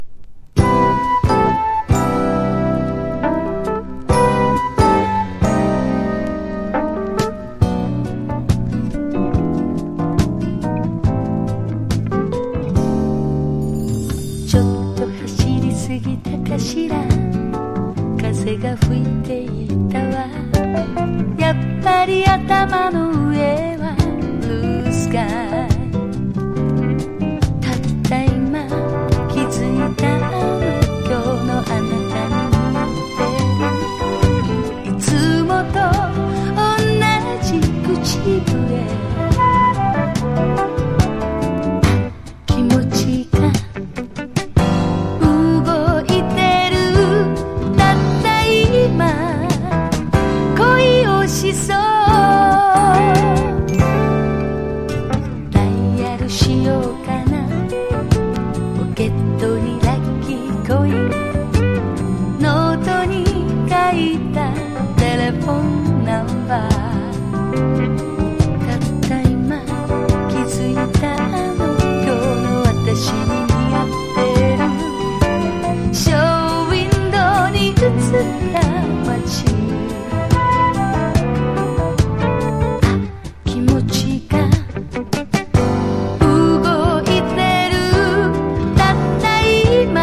JAPANESE LIGHT MELLOW名曲！！
CITY POP / AOR